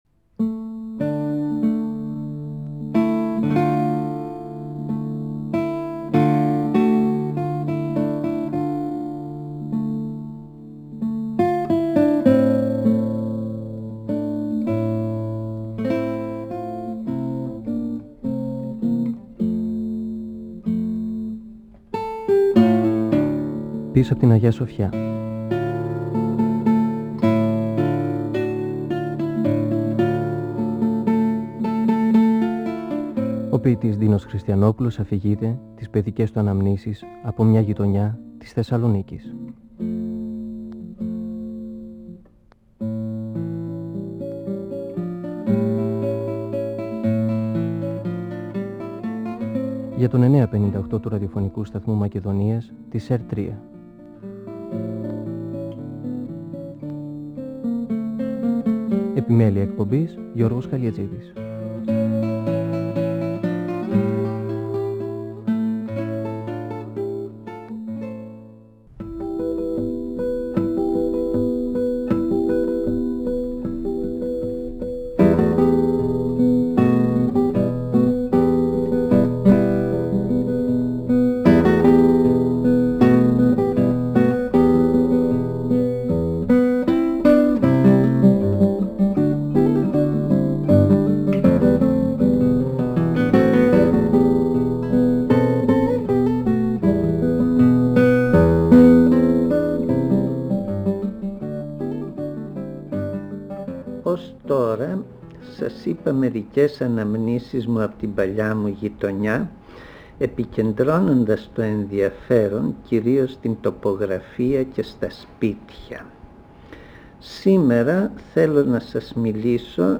Ο ποιητής Ντίνος Χριστιανόπουλος (1931-2020) μιλά για τις αναμνήσεις του από το μια παλιά γειτονιά της Θεσσαλονίκης, πίσω απ’ την Αγια-Σοφιά. Μιλά για τους συγκάτοικους και του γείτονες, την κοινή κουζίνα και το κοινό αποχωρητήριο.